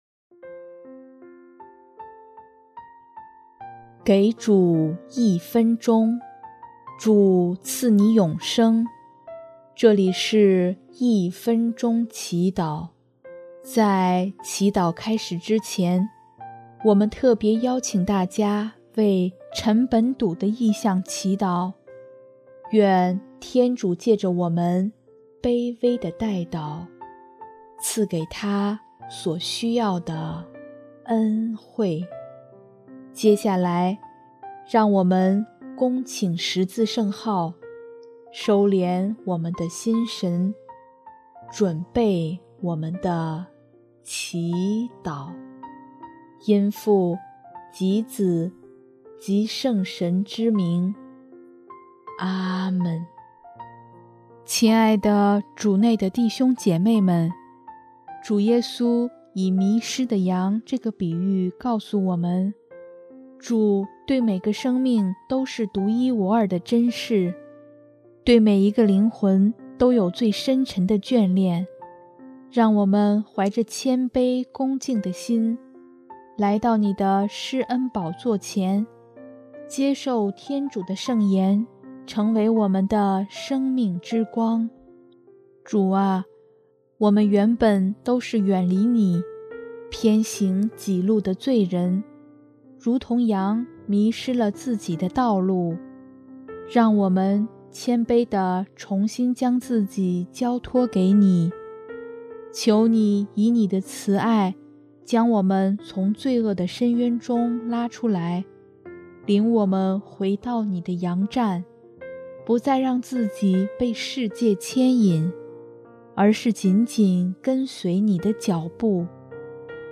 音乐： 第四届华语圣歌大赛参赛歌曲